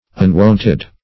Unwonted \Un*wont"ed\ ([u^]n*w[u^]nt"[e^]d), a.